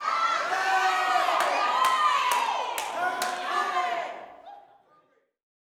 YELLS.wav